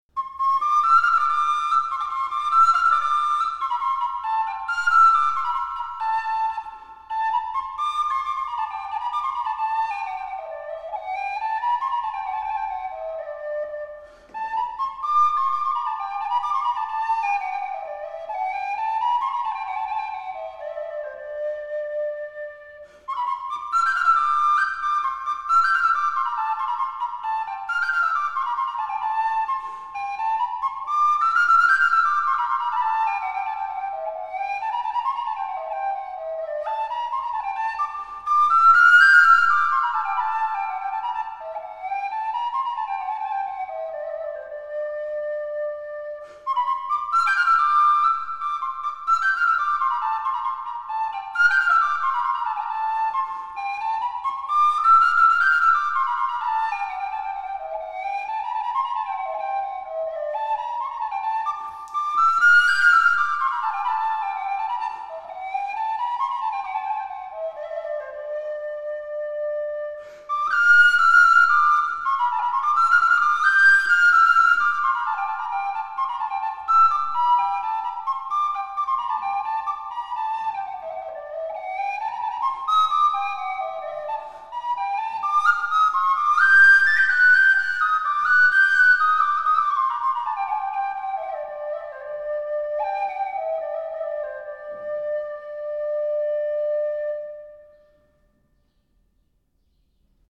trad. Sefardita
Medioevo flauto diritto